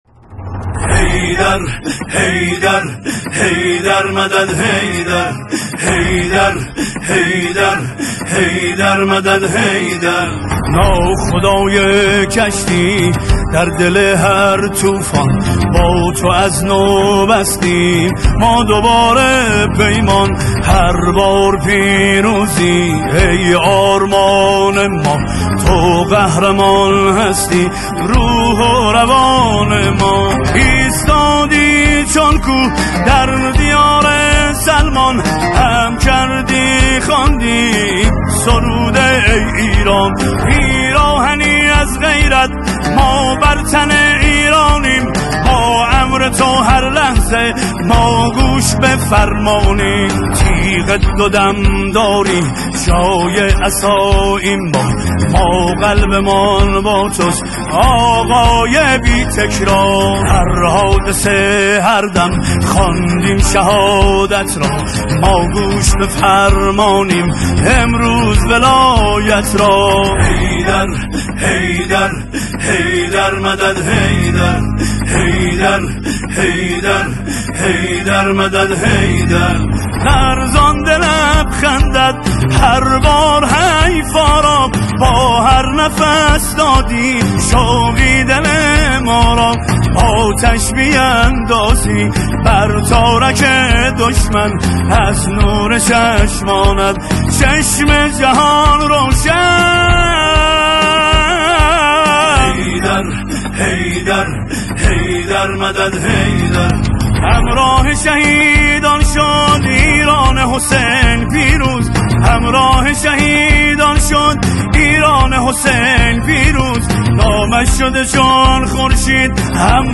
قطعه موسیقی
قطعه‌ای حماسی، آیینی